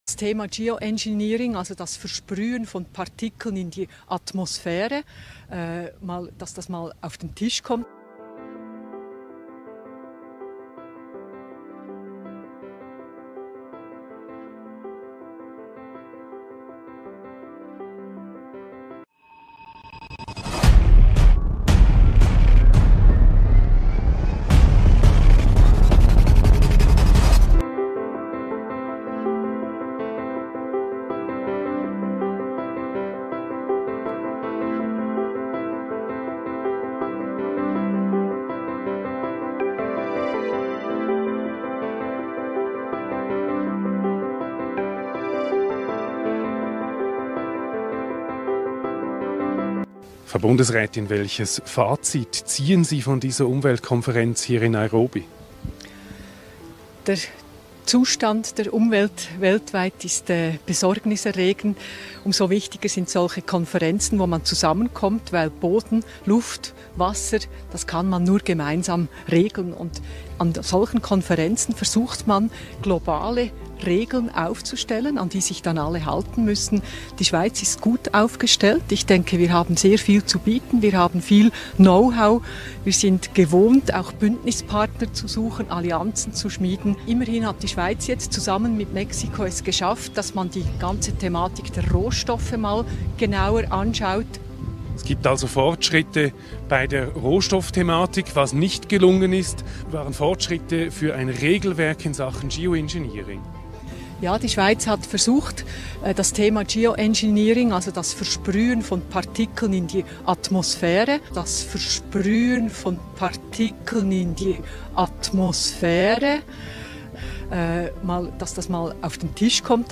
Interview der Schweizer Bundestätin Sommaruga an der Umwelt Konferenz in Nairobi Kenya...